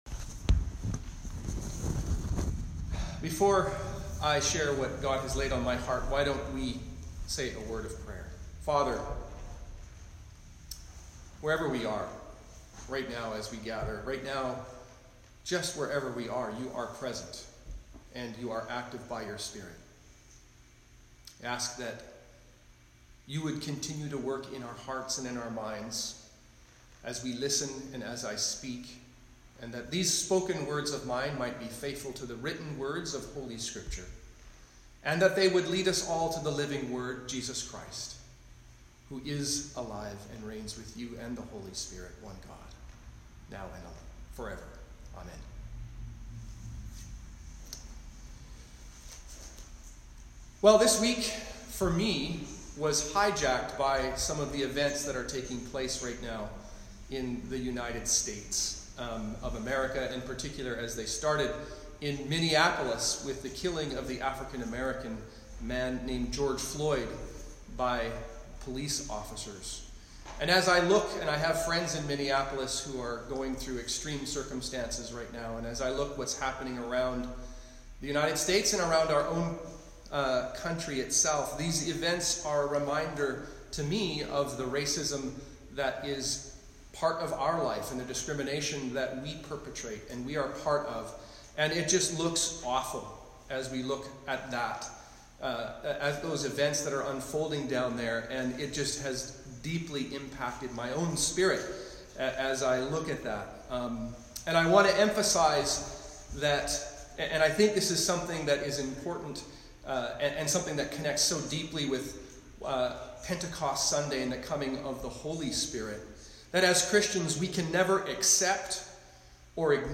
Sermons | St. Paul's Anglican Church
Sermon Notes